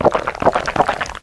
bathwater_drink.ogg